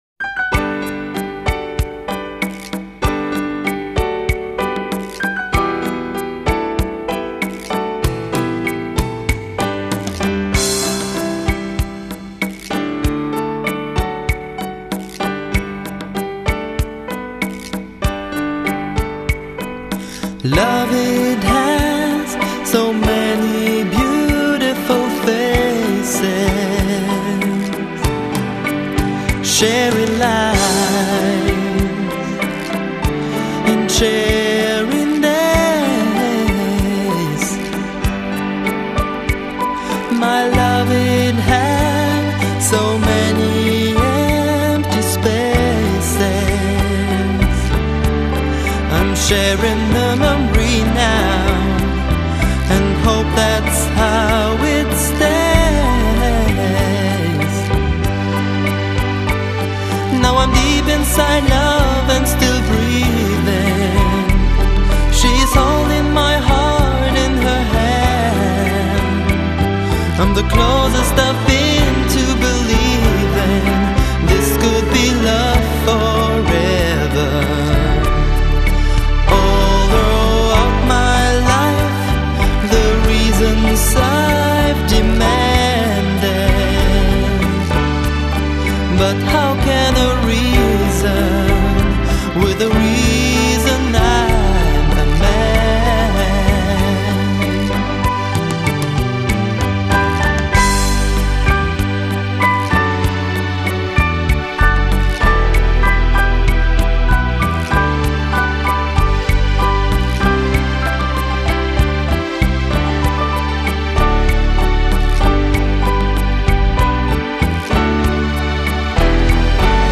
10 Rumba